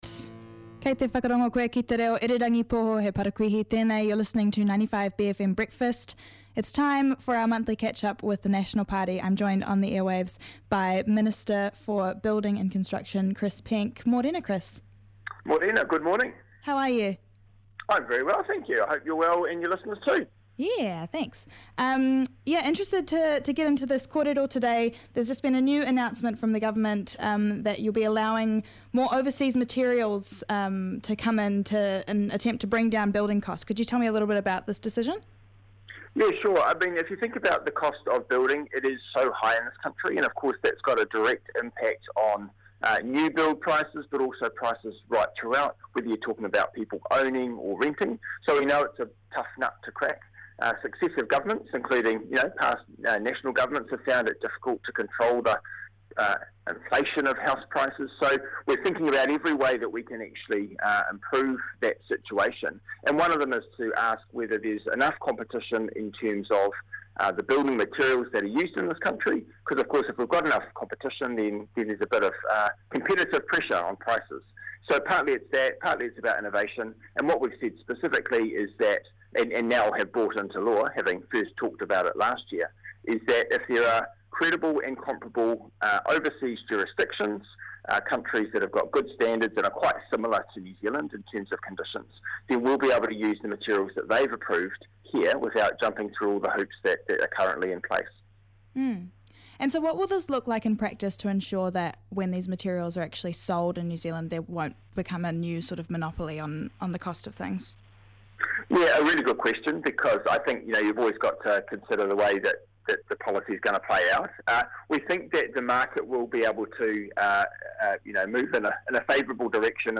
Listen back to feature interviews and performances from the 95bFM Breakfast Show.